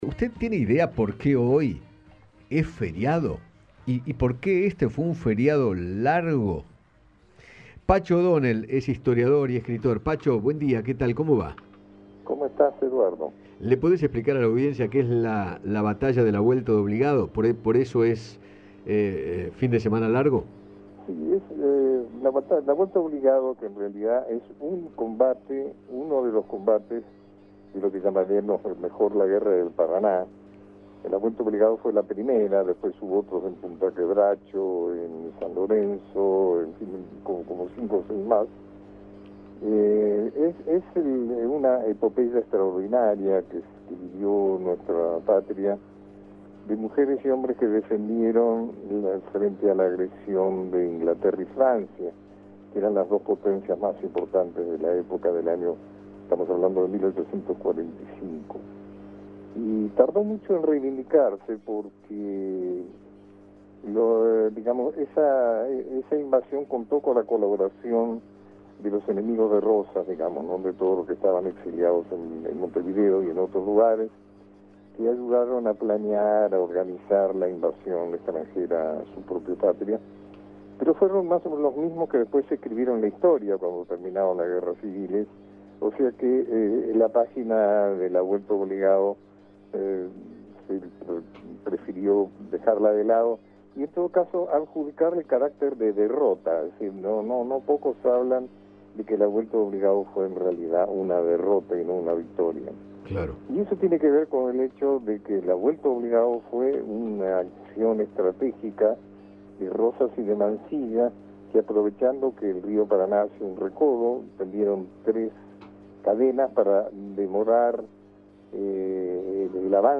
Pacho O’Donnell, escritor, historiador y psiquiatra, conversó con Eduardo Feinmann sobre la Vuelta de Obligado, batalla histórica de 1840 que permitió consolidar definitivamente la Soberanía Nacional.